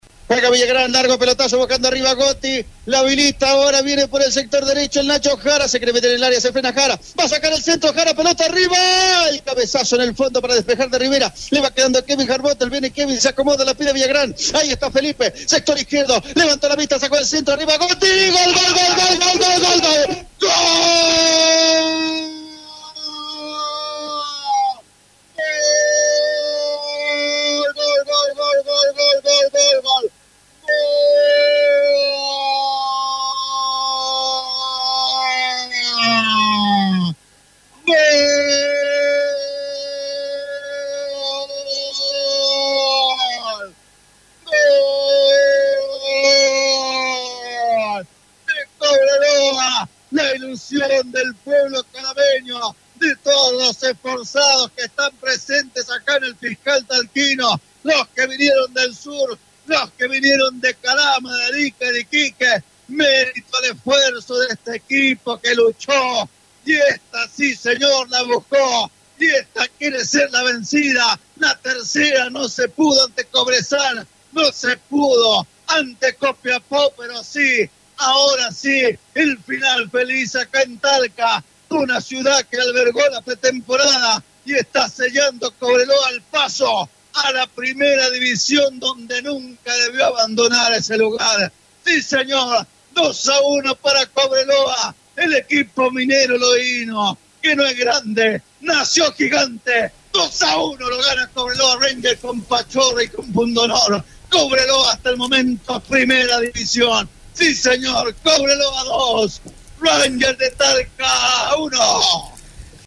[Audio] Revive los dramáticos y apasionantes relatos de los agónicos goles del ascenso de Cobreloa
Los locutores volvieron a estallar.